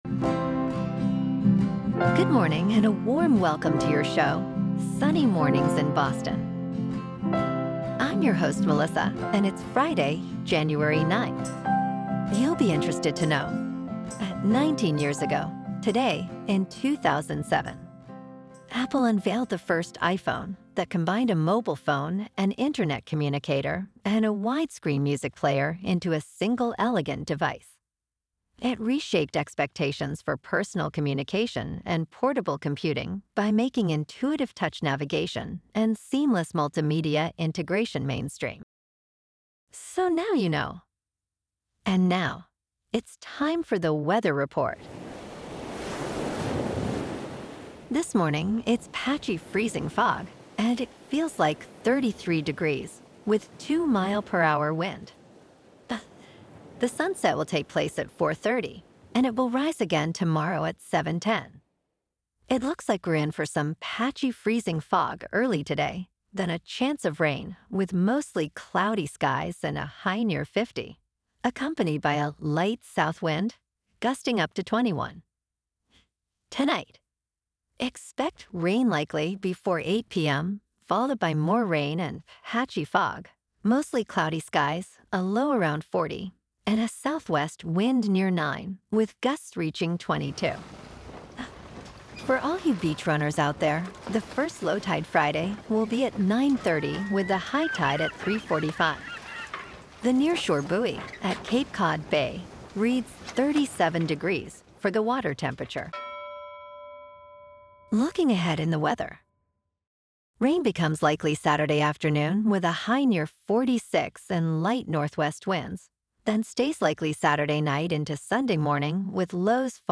The #1 Trusted Source for AI Generated News™